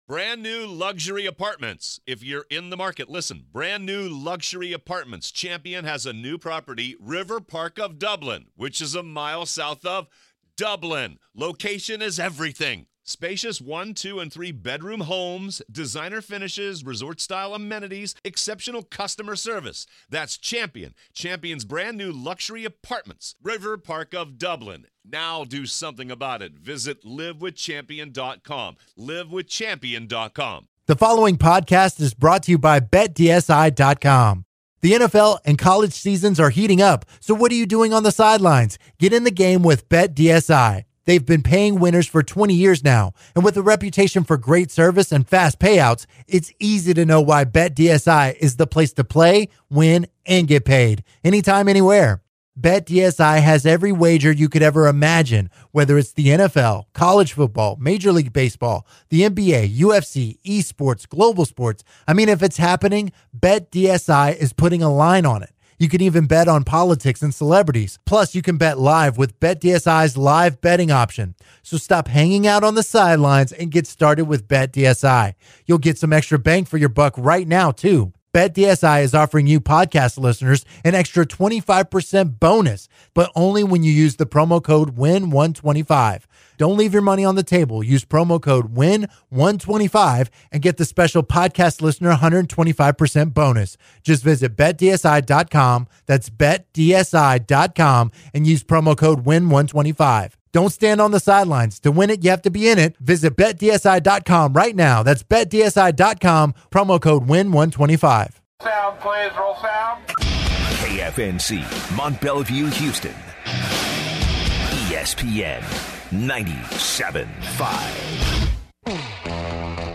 They guys take calls from listeners and discuss the Astros’ loss. They discuss the Astros’ bullpen issues and the difference in batting performances between the Astros and the Red Sox.